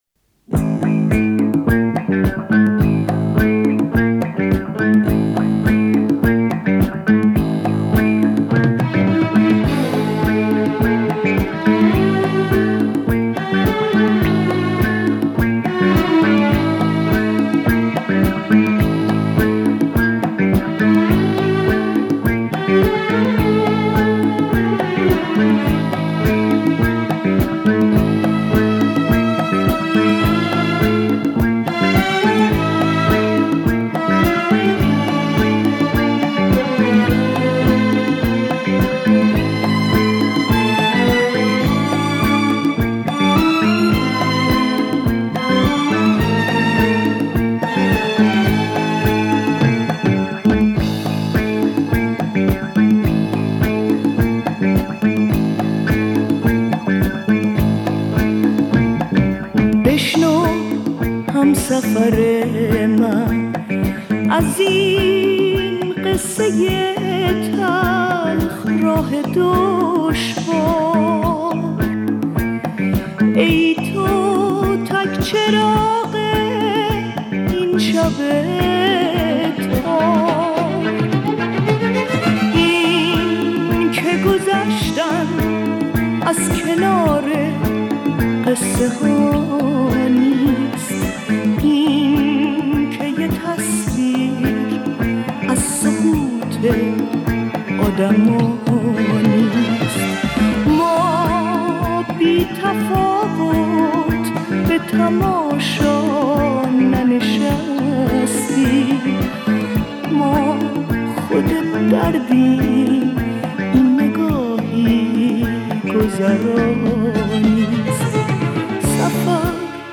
پاپ کلاسیک